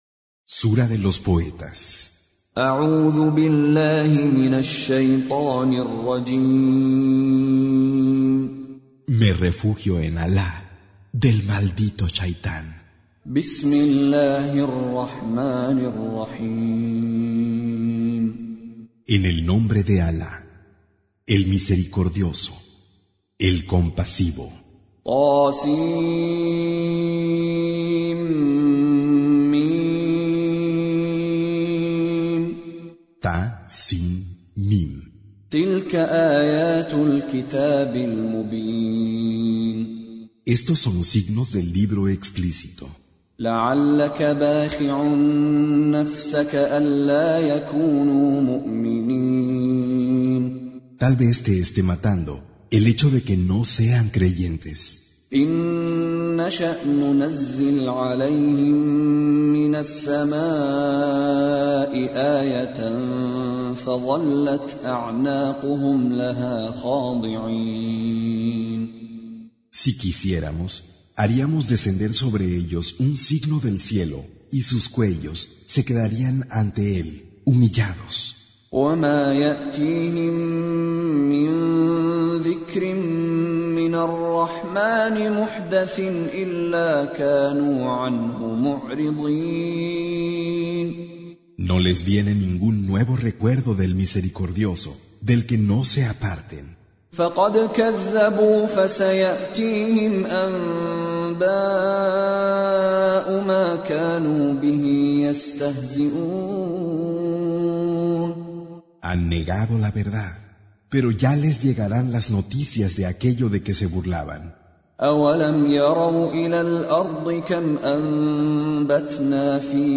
Surah Sequence تتابع السورة Download Surah حمّل السورة Reciting Mutarjamah Translation Audio for 26.